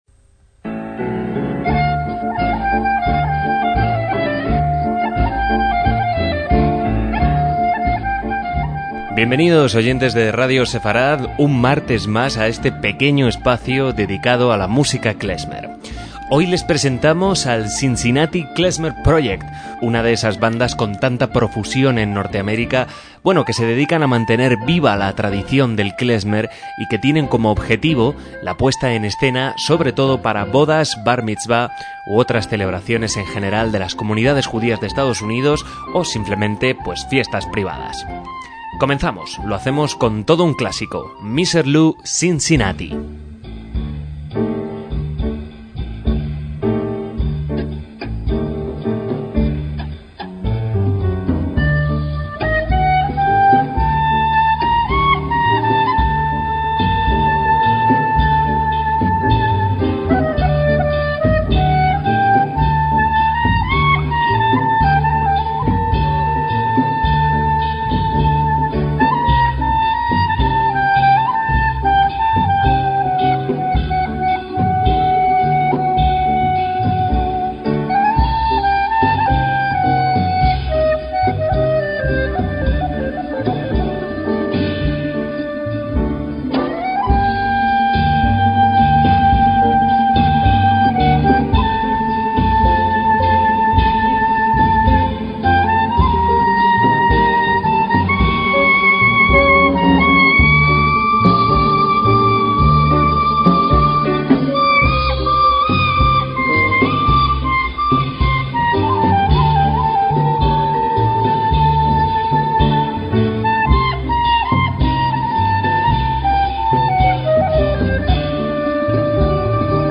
MÚSICA KLEZMER – Cincinnati Klezmer Project es un grupo que, desde 1993, interpreta música judía ashkenazí y que ha participado en muchos festivales, así como en celebraciones personales.
clarinete
mandolina ucraniana
guitarra y voz
batería
contrabajo